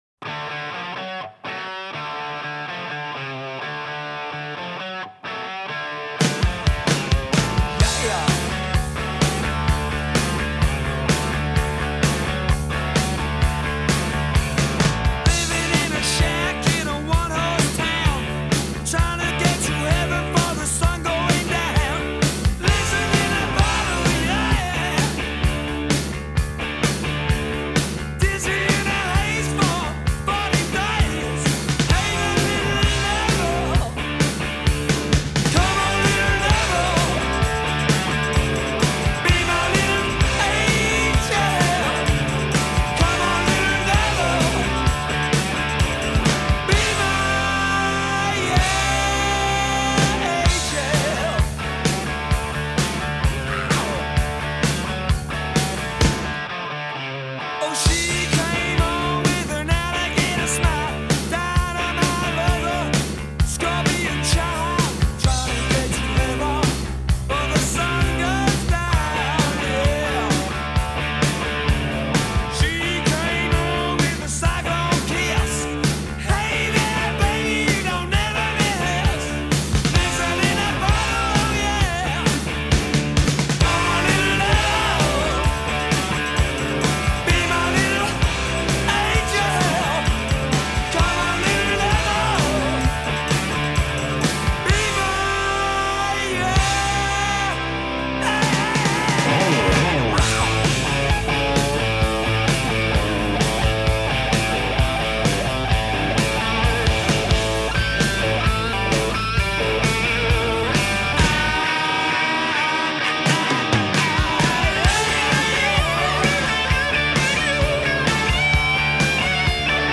Even the tambourine sounds pissed off.